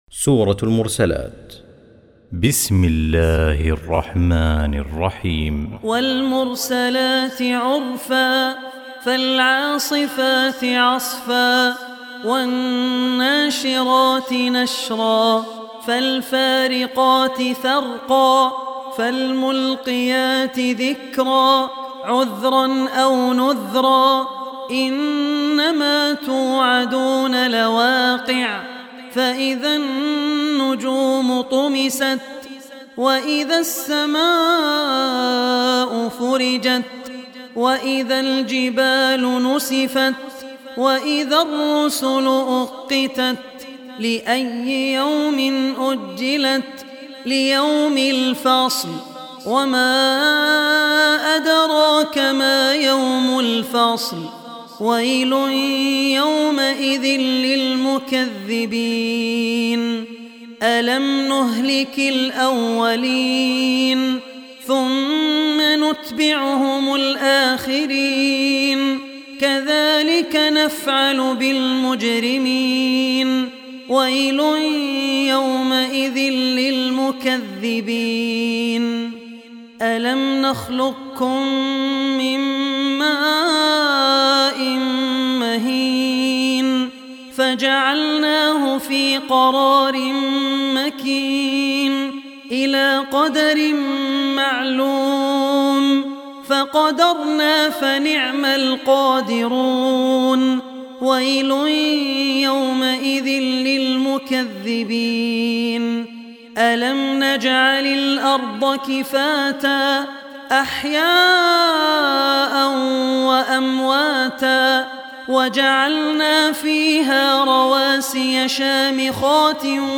Surah Mursalat Recitation by Al Ossi
Surah Mursalat, listen online mp3 tilawat / recitation in Arabic in the beautiful voice of Sheikh Abdul Rehman Al Ossi.